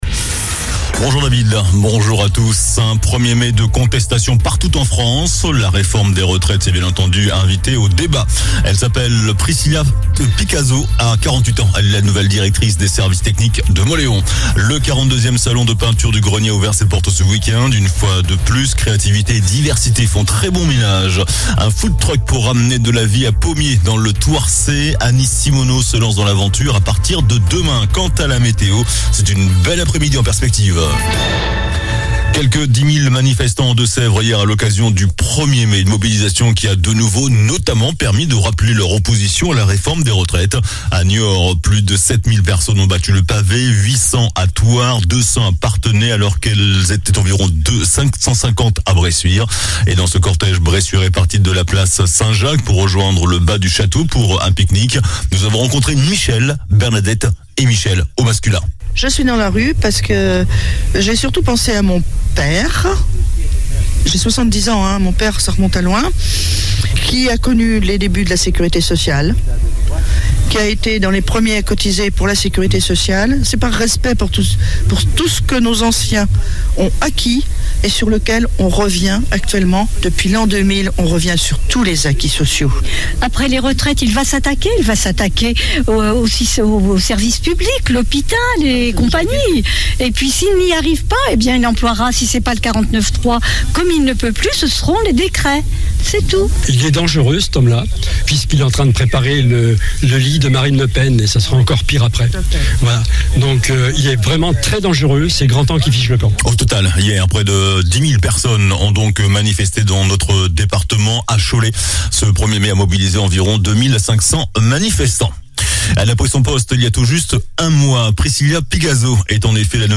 JOURNAL DU MARDI 02 MAI ( MIDI )